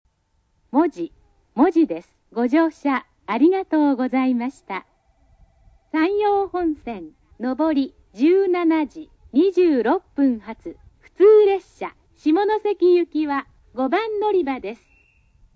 スピーカー：UNI-PEX・SC-10JA（ソノコラム・ミニ型）
音質：C
６番のりば 到着+のりかえ放送 (76KB/15秒)